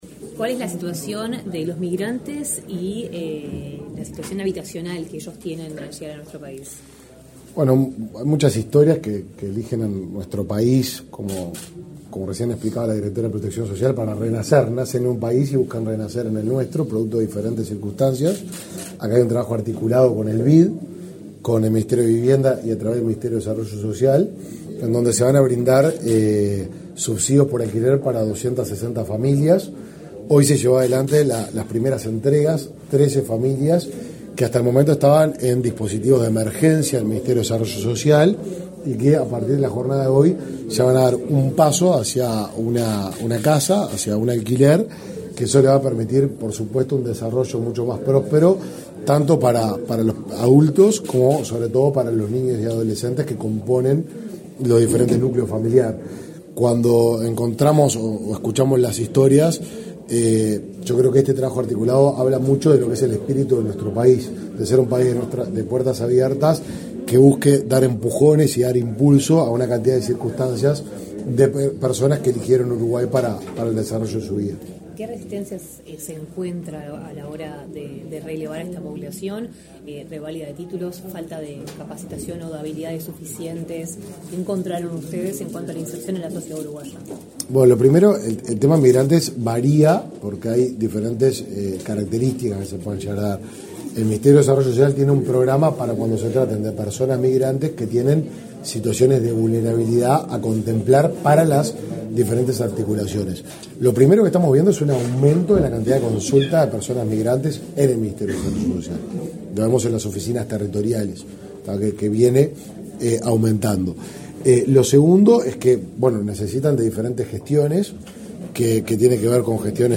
Declaraciones a la prensa de ministro de Desarrollo Social, Martín Lema
Declaraciones a la prensa de ministro de Desarrollo Social, Martín Lema 23/10/2023 Compartir Facebook X Copiar enlace WhatsApp LinkedIn Los ministerios de Vivienda y Ordenamiento Territorial y de Desarrollo Social entregaron, este 23 de octubre, certificados de subsidios de alquiler a 13 familias migrantes, en el marco de un convenio firmado por ambas. Tras la ceremonia, el ministro Martín Lema realizó declaraciones a la prensa.